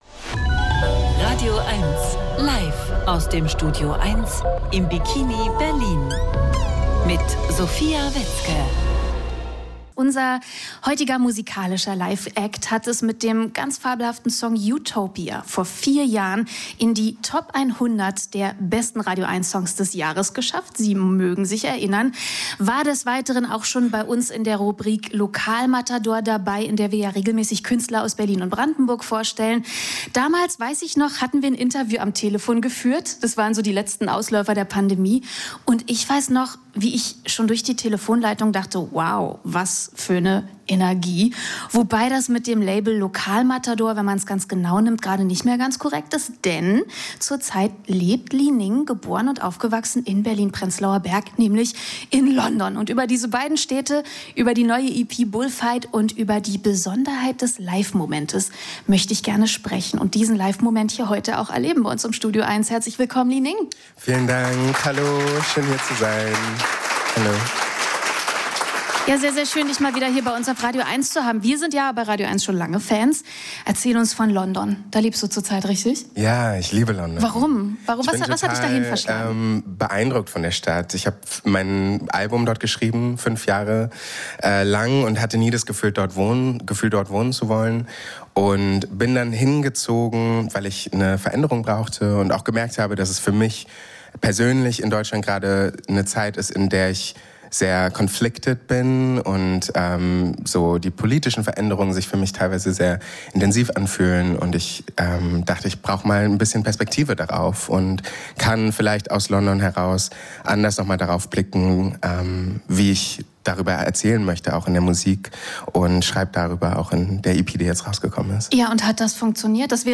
Musik-Interviews